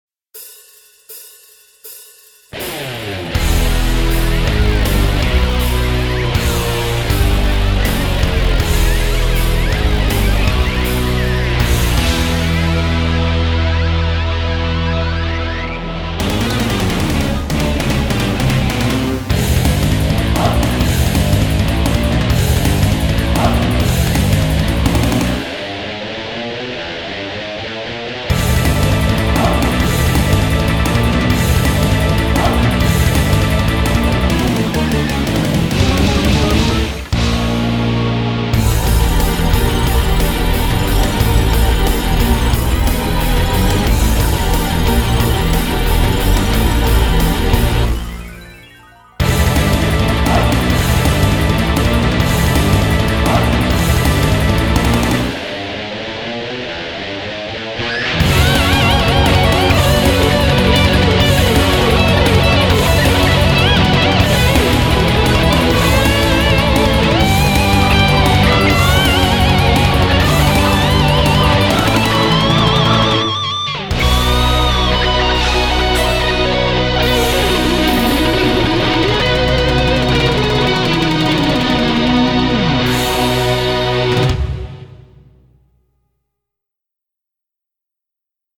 BPM80-300